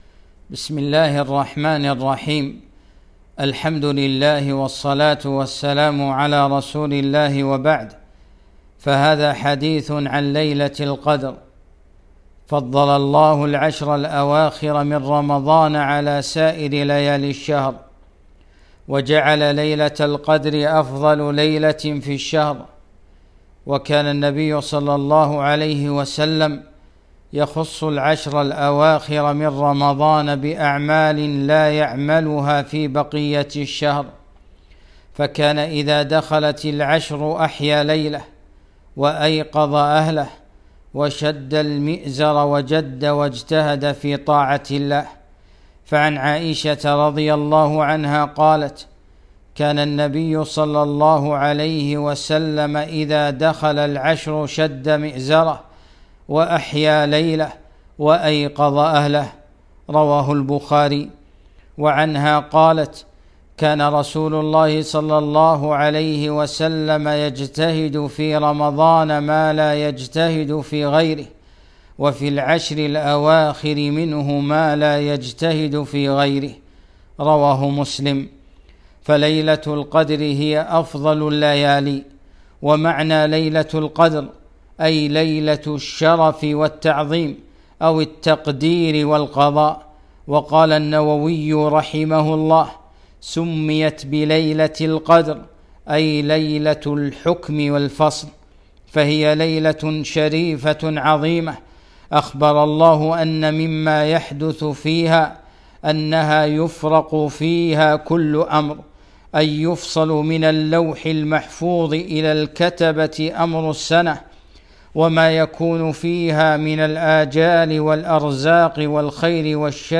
ليلة القدر - كلمة